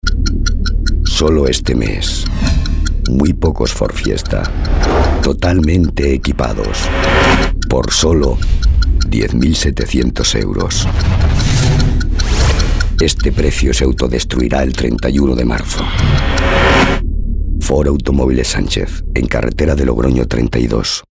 voz grave, adulto, constitucional
Sprechprobe: Industrie (Muttersprache):